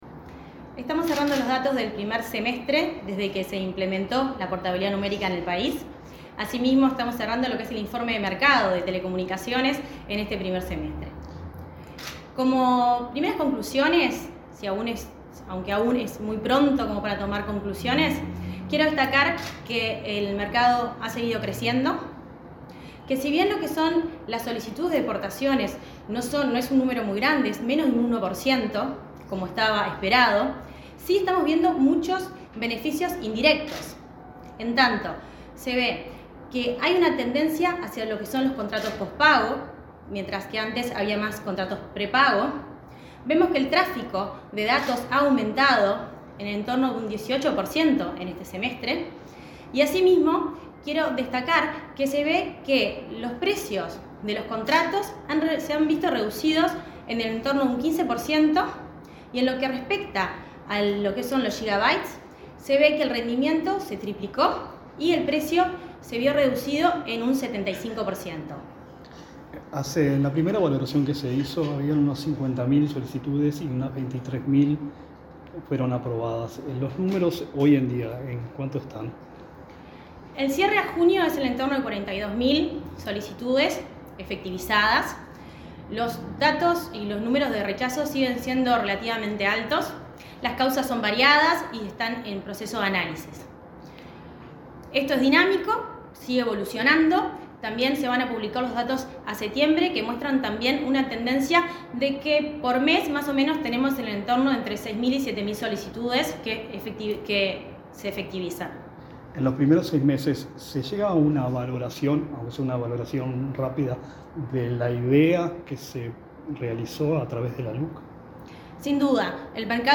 Entrevista a la presidenta de Ursec, Mercedes Aramendía
La presidenta de la Unidad Reguladora de Comunicaciones (Ursec), Mercedes Aramendía, dialogó con Comunicación Presidencial acerca del balance del